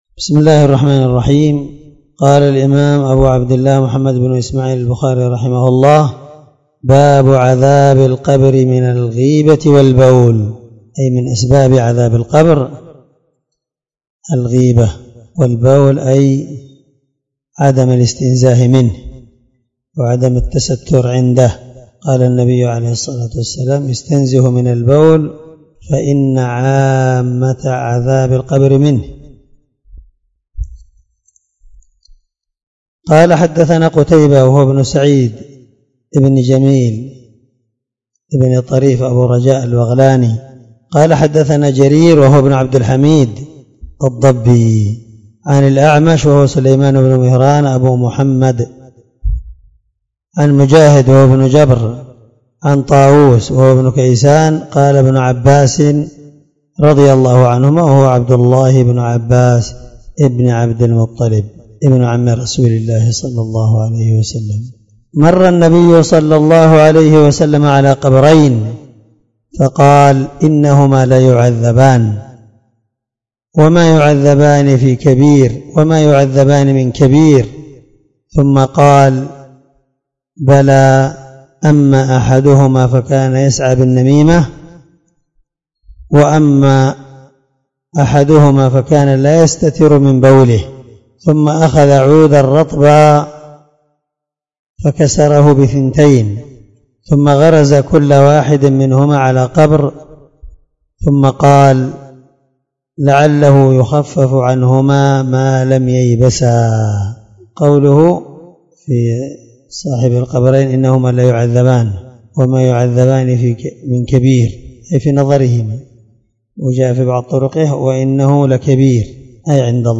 792الدرس 65من شرح كتاب الجنائز حديث رقم(1378-1379 )من صحيح البخاري
دار الحديث- المَحاوِلة- الصبيحة.